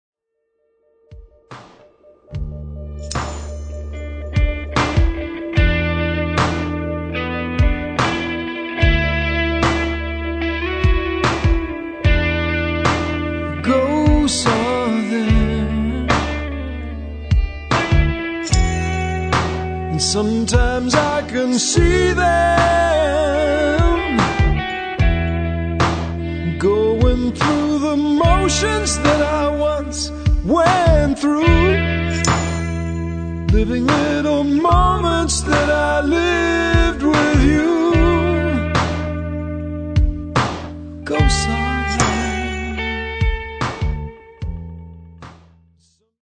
Instruments: Guitars, Bass, Vocals, Found Sounds